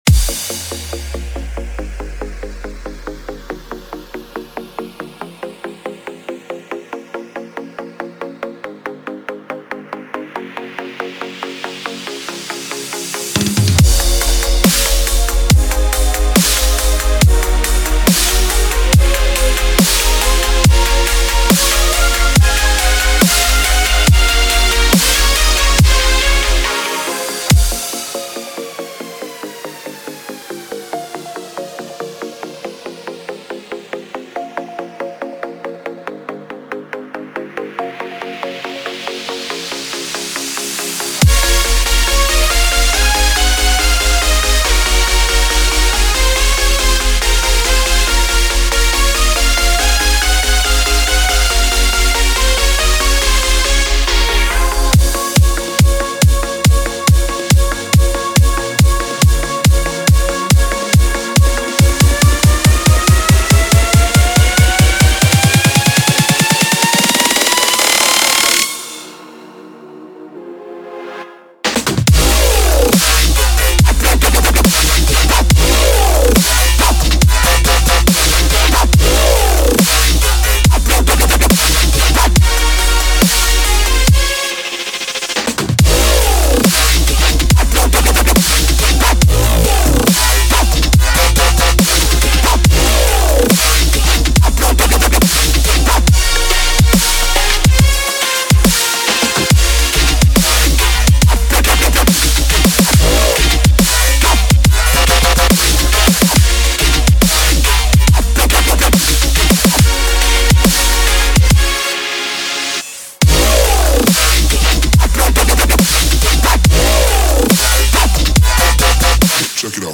Жанр: Dubstep